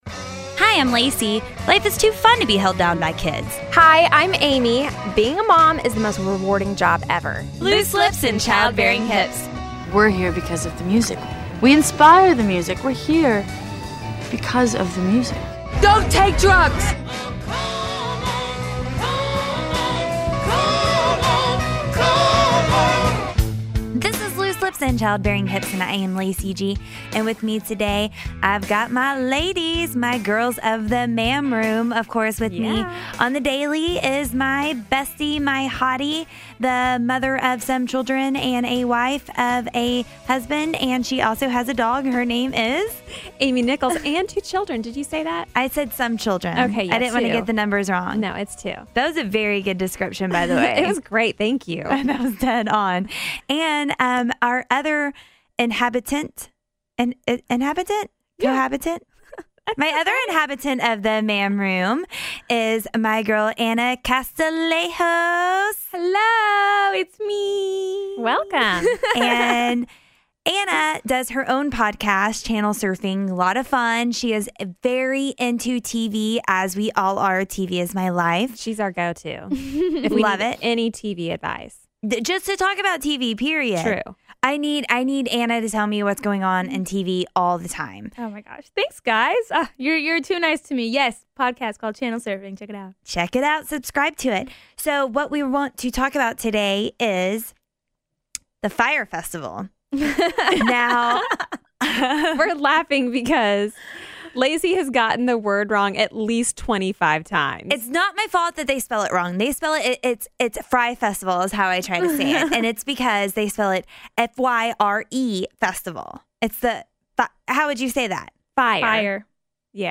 We haven't messed up that badly but we do have some epic fails of our own. Listen to the ladies of the ma'am room